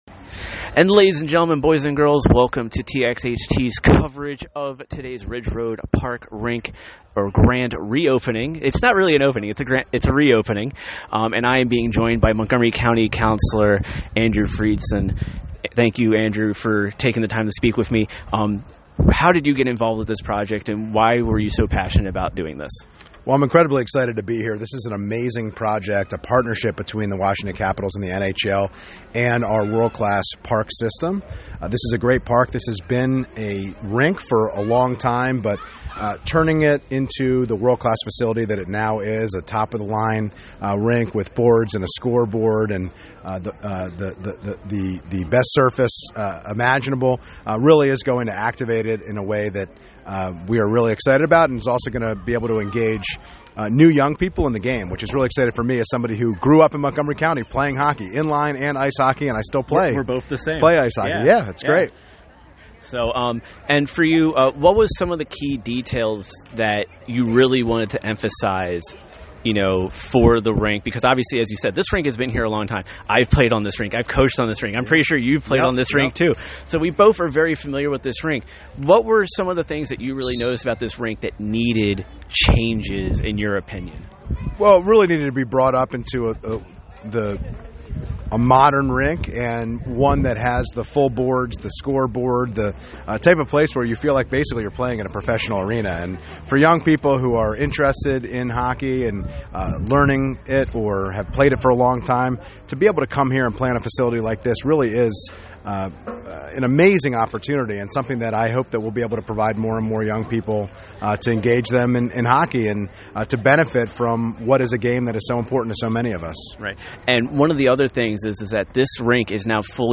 During the event I was able to speak with some of the key people that helped make today possible, and here are our interviews with them.
Andrew Friedson (District 1 Council Member)